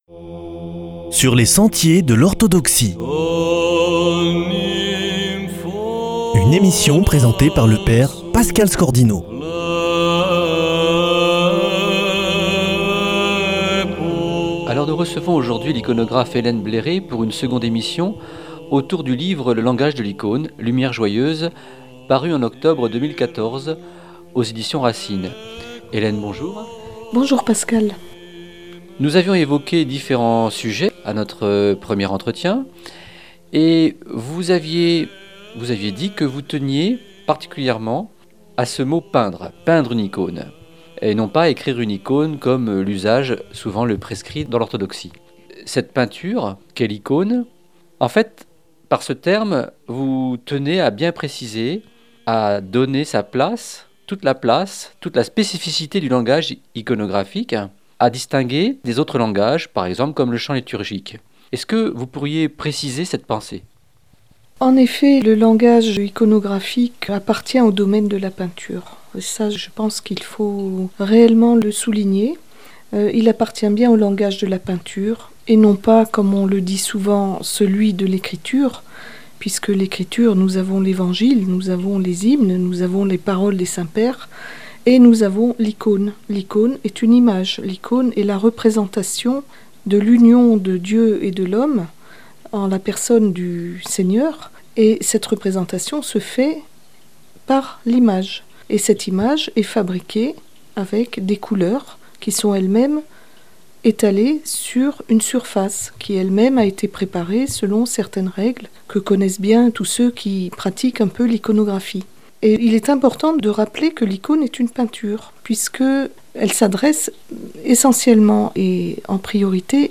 Musique de pause : "Mamao Chveno", მამო ჩვენო (Notre Père" en géorgien d’un chœur de moniales).